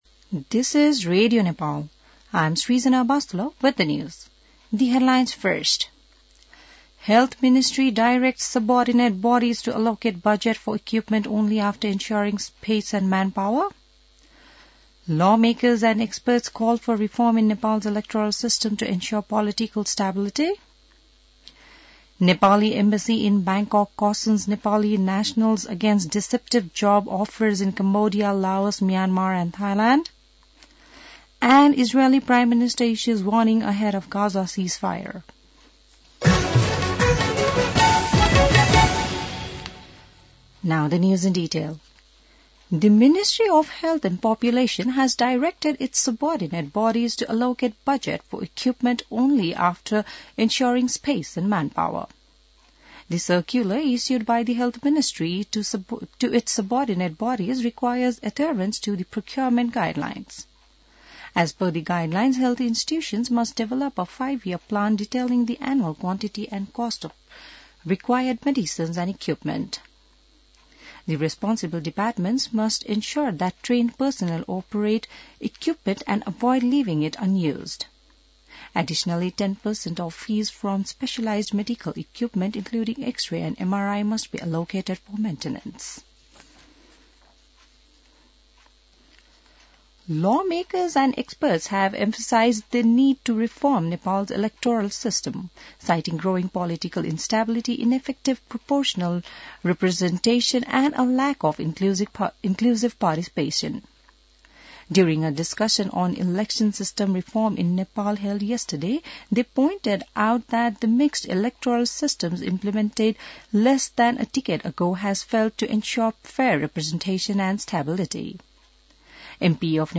बिहान ८ बजेको अङ्ग्रेजी समाचार : ७ माघ , २०८१